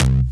Shockone Bass.wav